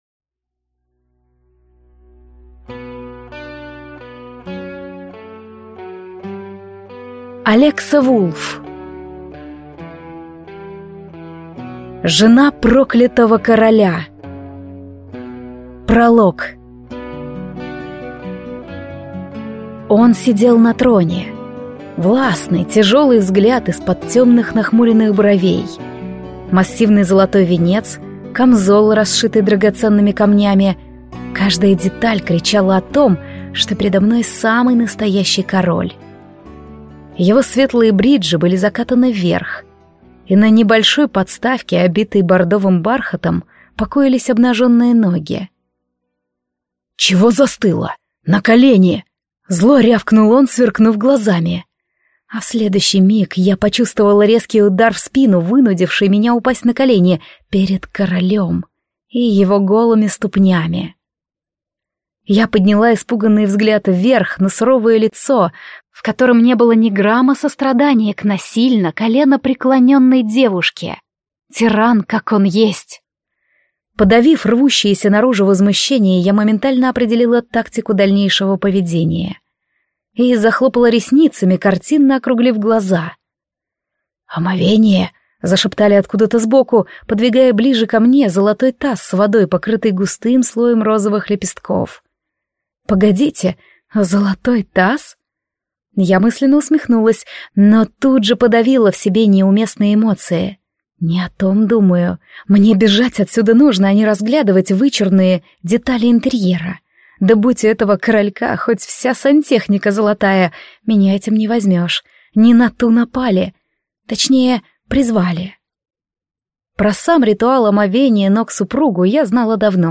Аудиокнига Жена проклятого короля | Библиотека аудиокниг